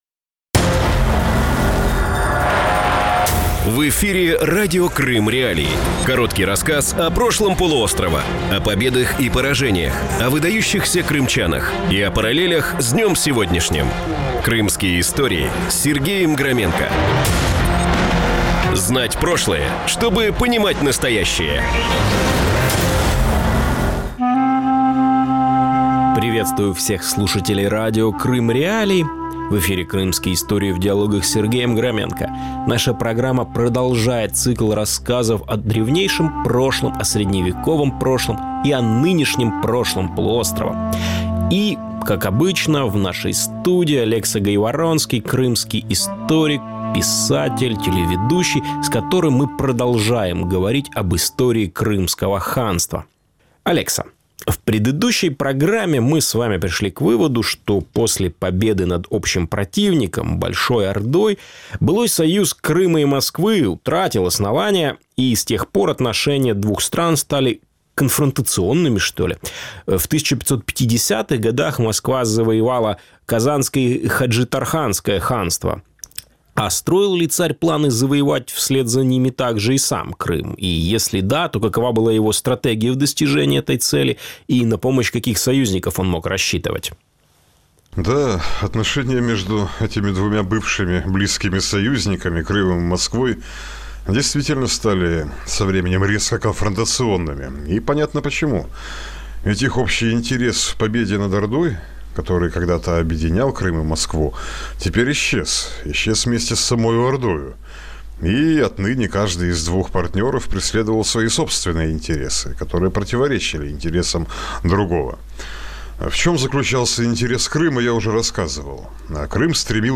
Новый цикл Крымских.Историй в диалогах рассказывает об истории Крыма с древнейших времен до наших дней.